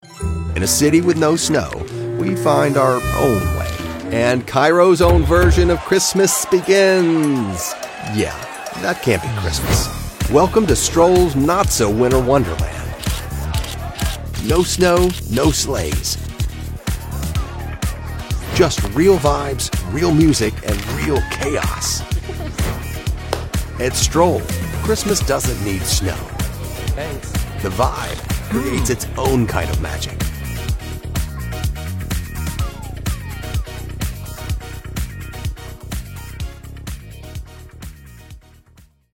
Upbeat High Energy Youthful Entertainment Online Ad
US Neutral, General American, Southern, Southwestern, Texas, Trans-Atlantic, Western
Middle Aged